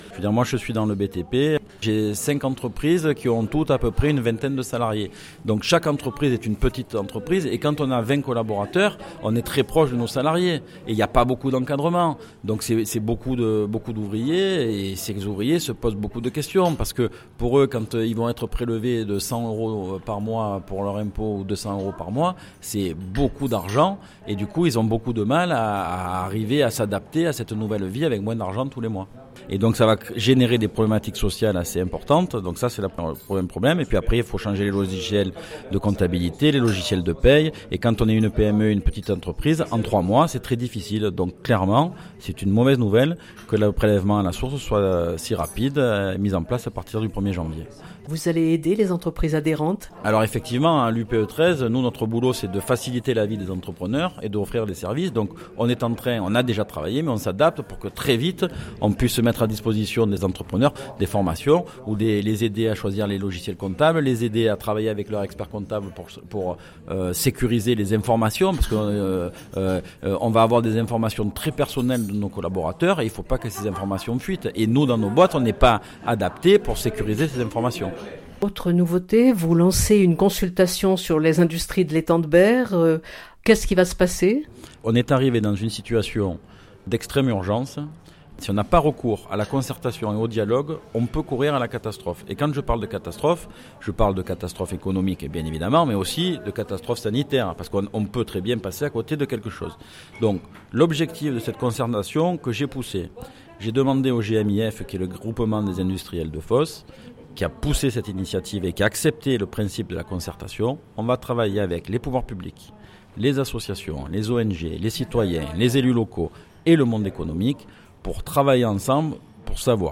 lors d’une rencontre avec la presse